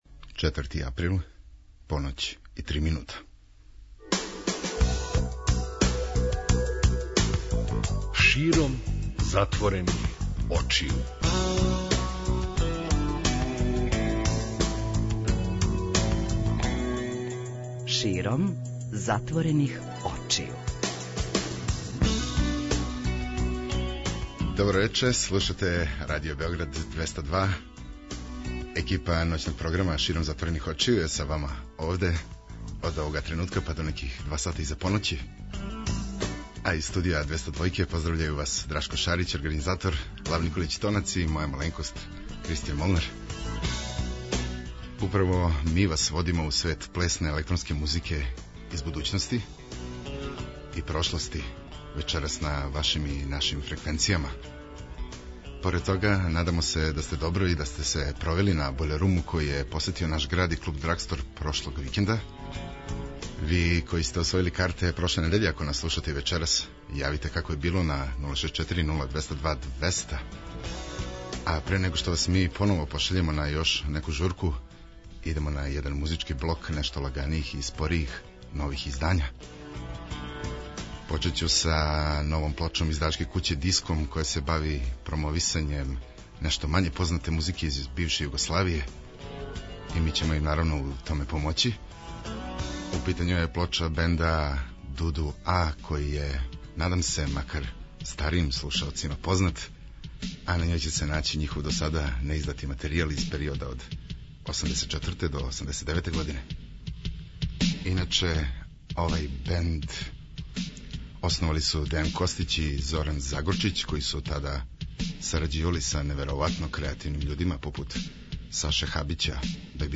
Ноћни програм Београда 202